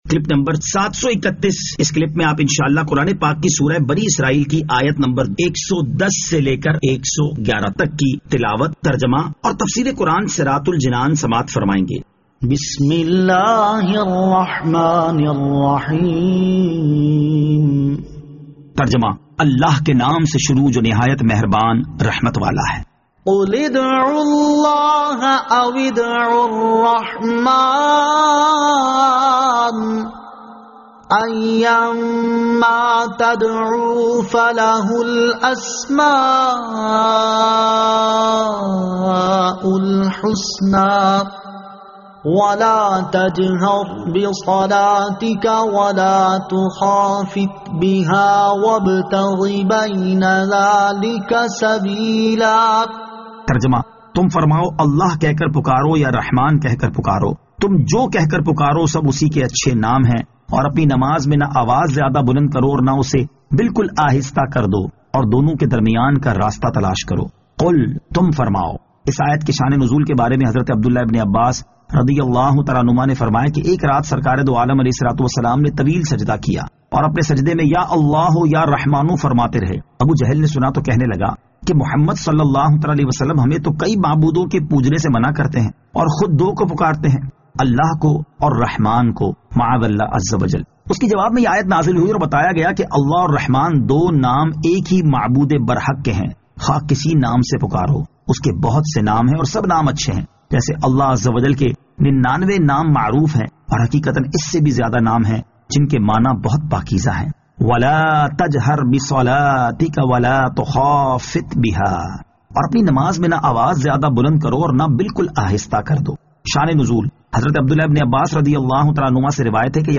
Surah Al-Isra Ayat 110 To 111 Tilawat , Tarjama , Tafseer